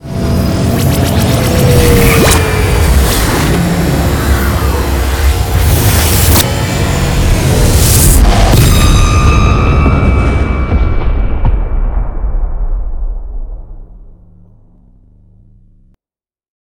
wormhole2.ogg